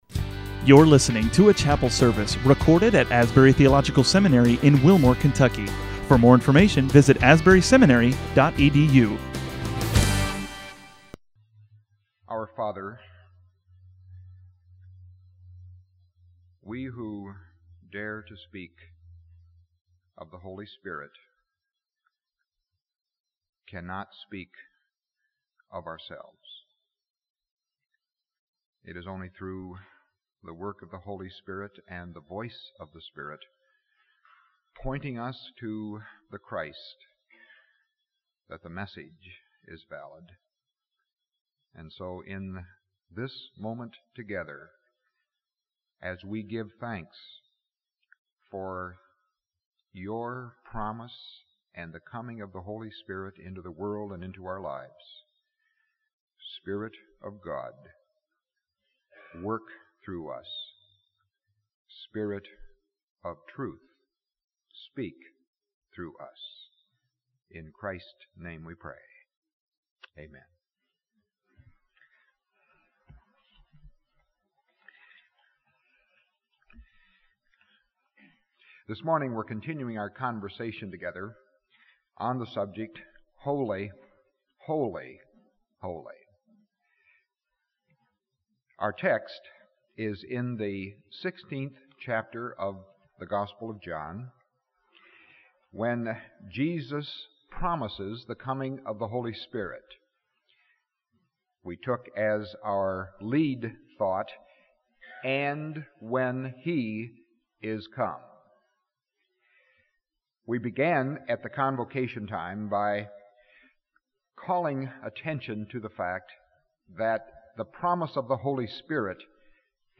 Faculty chapel services, 1984